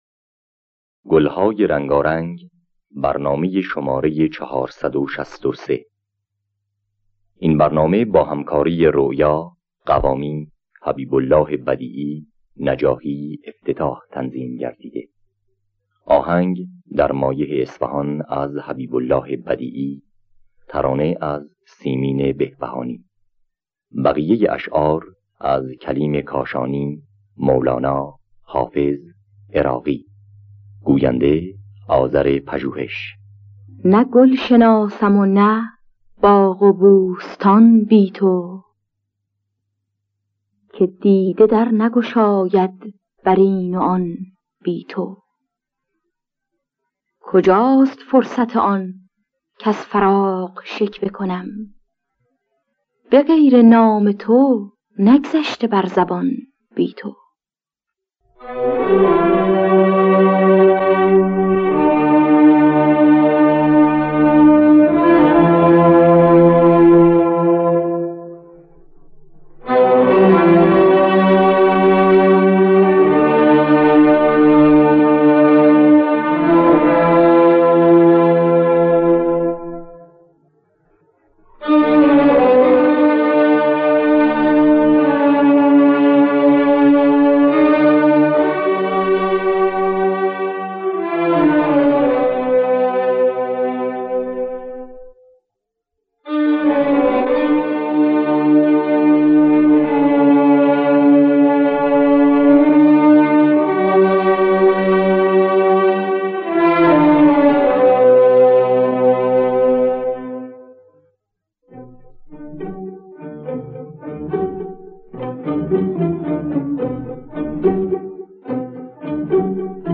گلهای رنگارنگ ۴۶۳ - بیات اصفهان